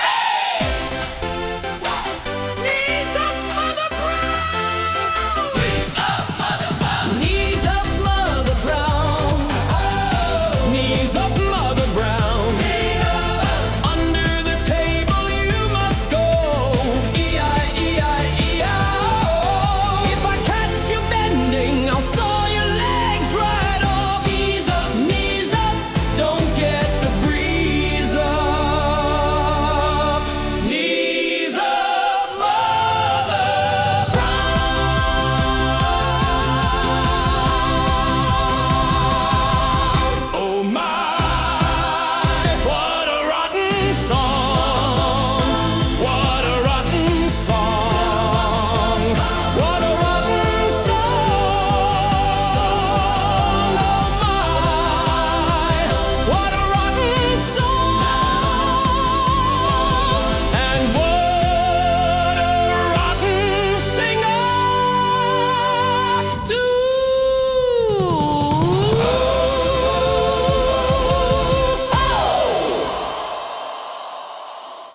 Instead, he has managed to get AI to sing them. There are 2 versions, one operatic and one in popular music style.
🎵 Play/Stop Knees Up Charts